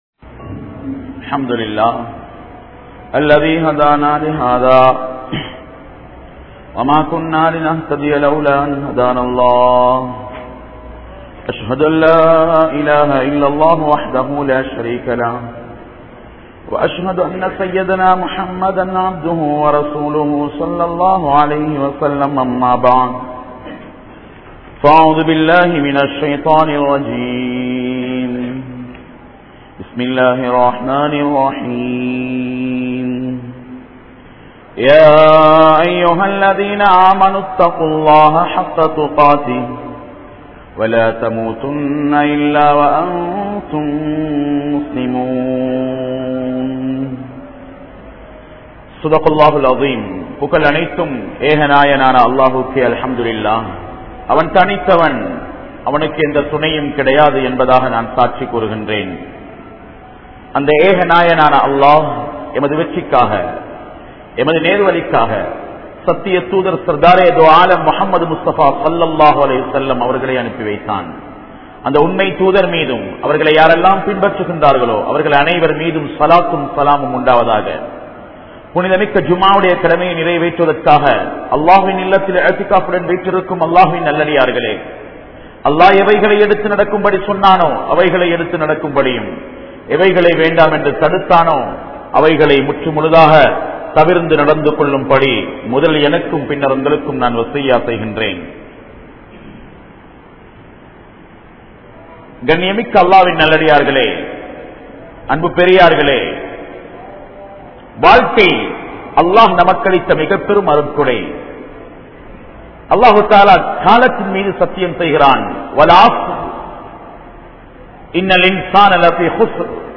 Vaalifaththin Soathanai Pengalaa? (வாலிபத்தின் சோதனை பெண்களா??) | Audio Bayans | All Ceylon Muslim Youth Community | Addalaichenai